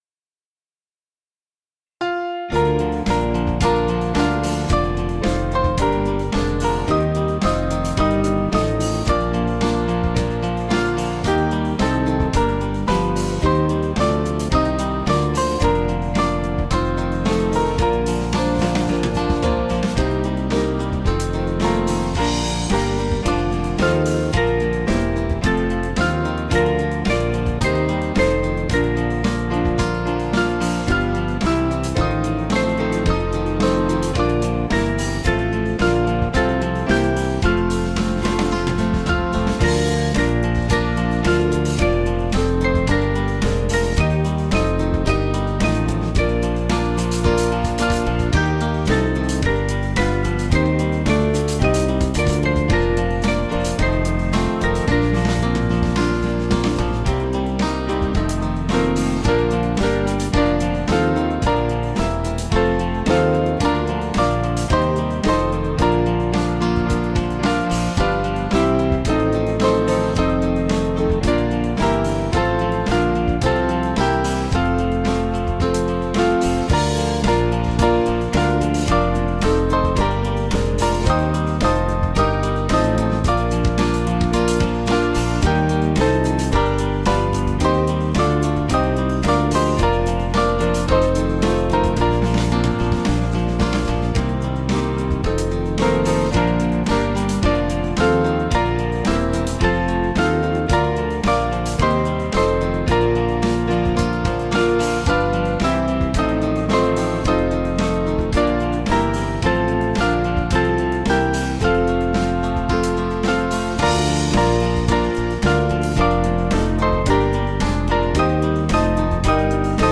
Small Band
(CM)   3/Bb 486.7kb